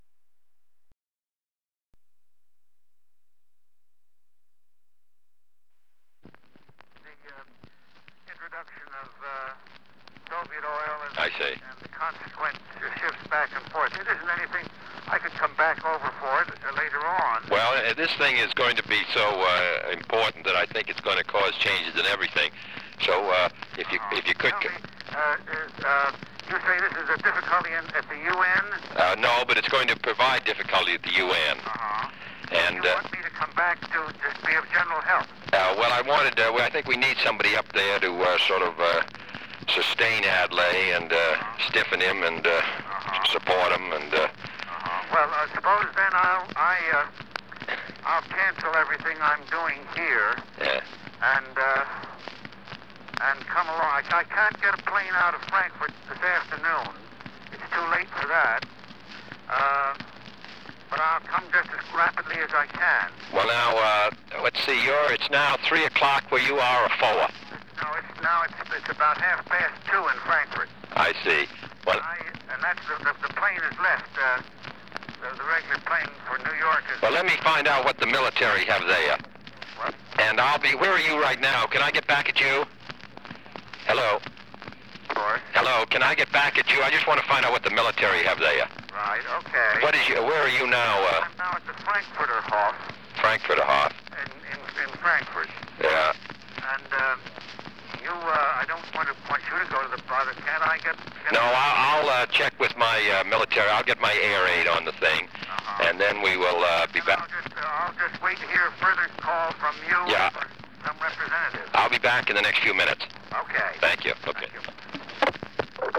Conversation with John McCloy
Secret White House Tapes | John F. Kennedy Presidency Conversation with John McCloy Rewind 10 seconds Play/Pause Fast-forward 10 seconds 0:00 Download audio Previous Meetings: Tape 121/A57.